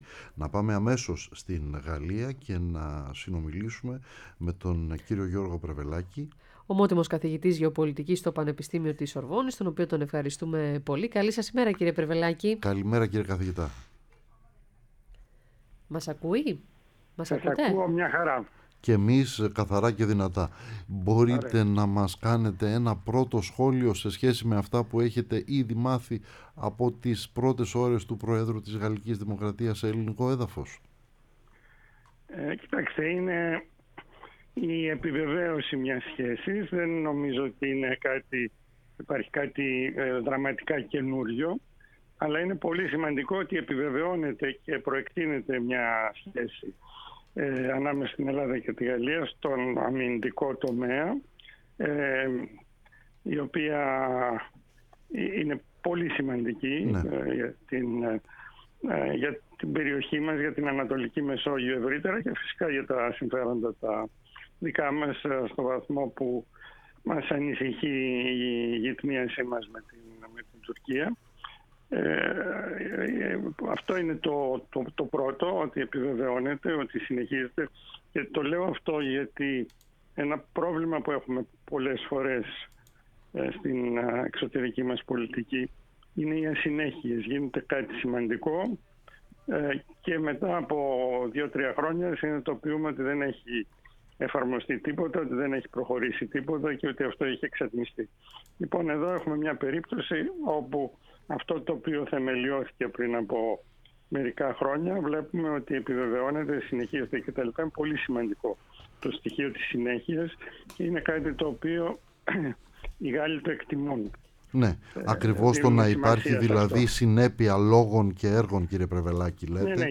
μίλησε στην εκπομπή “Ραντάρ”